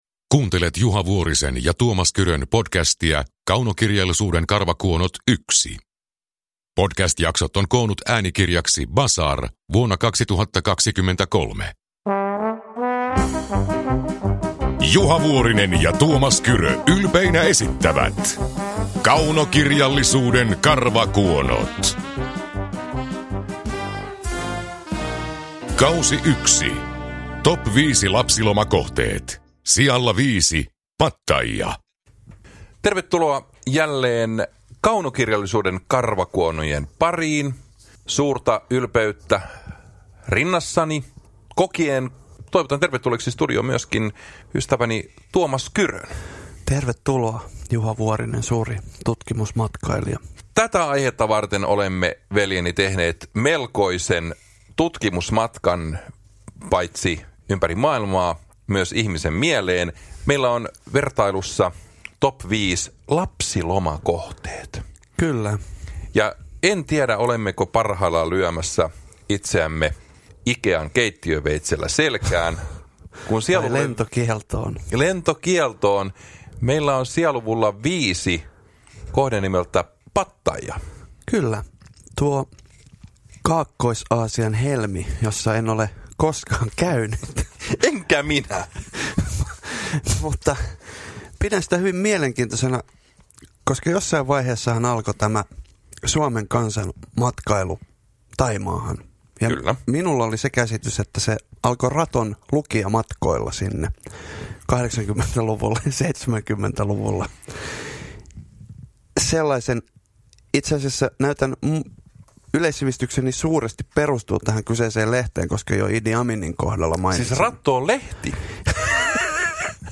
Kaunokirjallisuuden karvakuonot K1 – Ljudbok
Uppläsare: Tuomas Kyrö, Juha Vuorinen